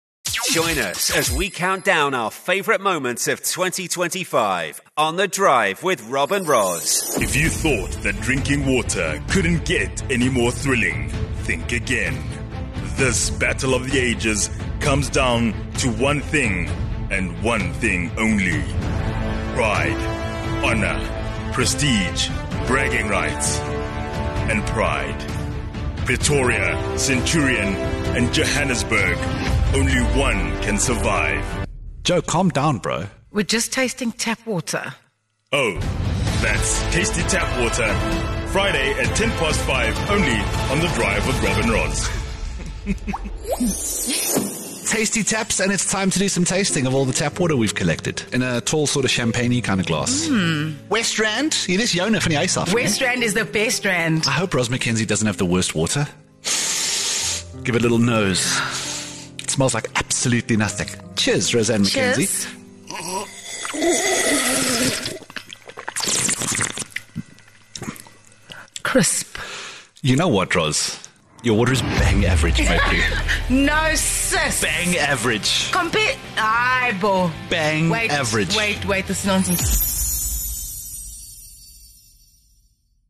'More Music You Love' features heavily in this drive-time show, so you can expect a whole lot of music mixed in with popular topics like the weird things we all do, or breaking exclusive interviews that give us the story behind the story.